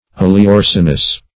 [1913 Webster] -- O`le*o*res"in*ous, a.